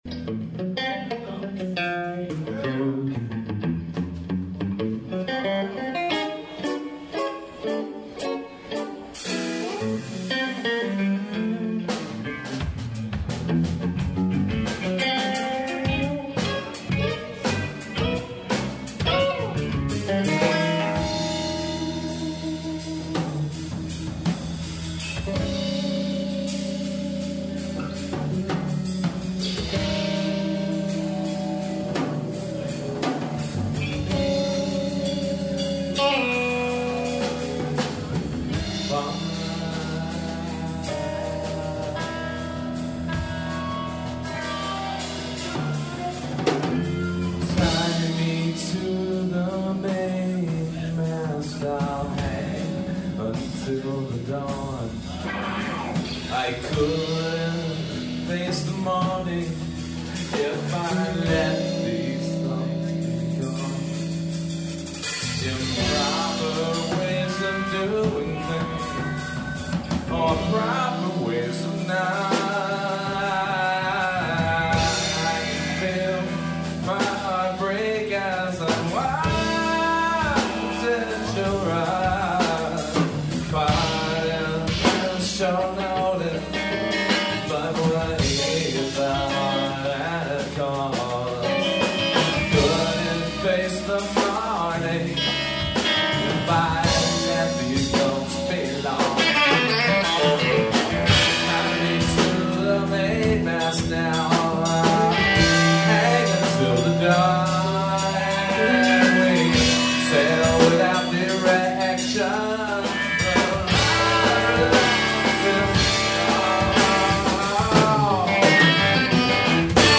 group improvisation
violin
bassoon
trombone
flute
vibes, percussion
keyboards, Rhodes